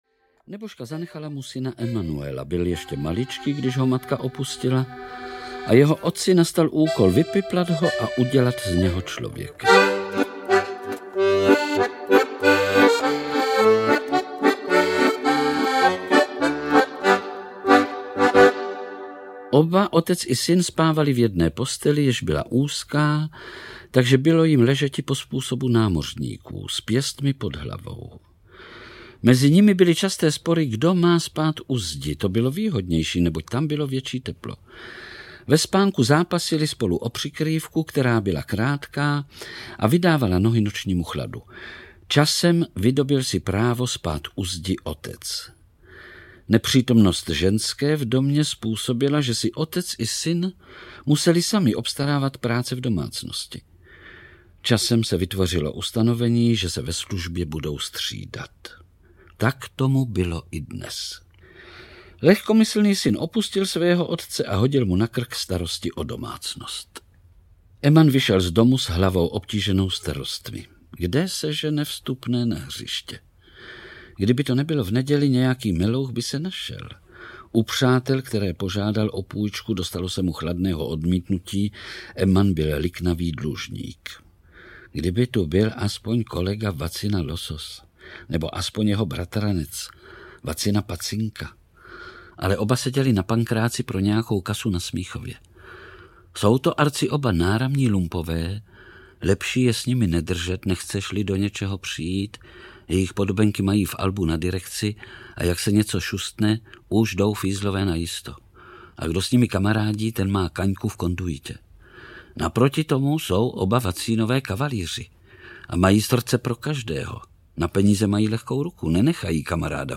Ukázka z knihy
• InterpretVlastimil Brodský, Jaroslava Adamová, Karel Höger, František Filipovský, Jan Kanyza, Miloslav König, Radúz Mácha, Igor Orozovič